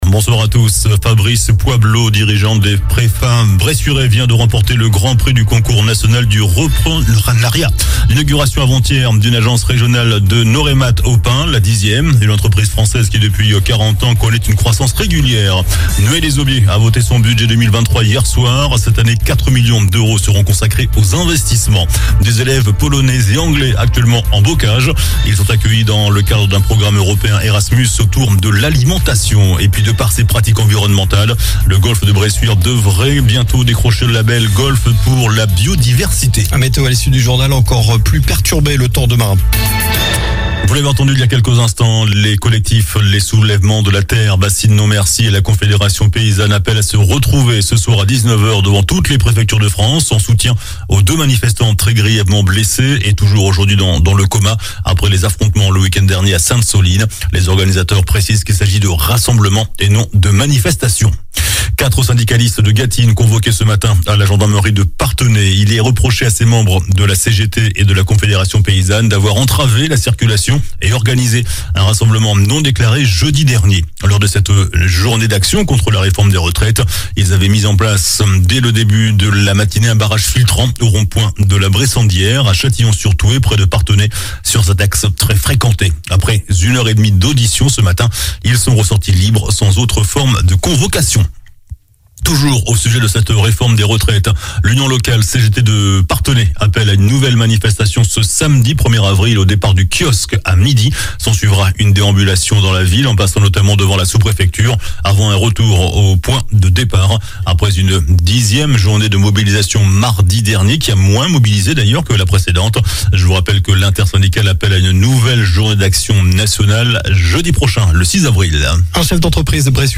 JOURNAL DU JEUDI 30 MARS ( SOIR )